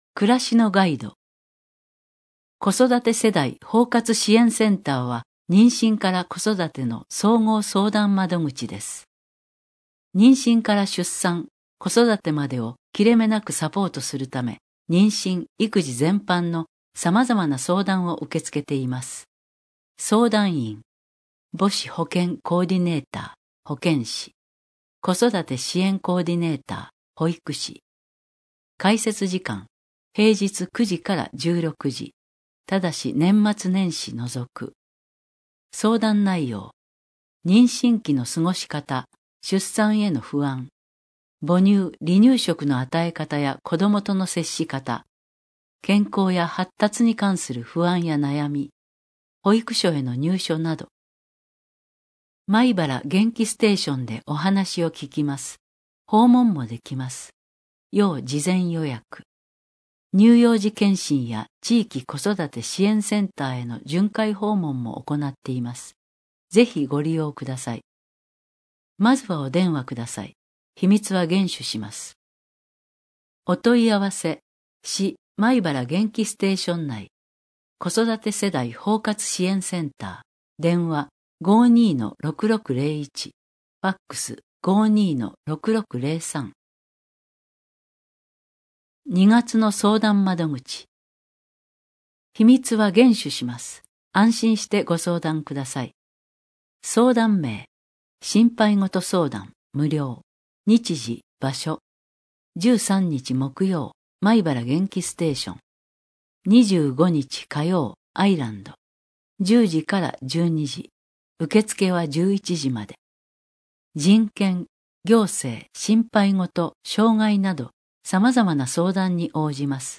視覚障がい者用に広報まいばらを音訳した音声データを掲載しています。 音声データは、音訳ボランティアグループのみなさんにご協力をいただき作成しています。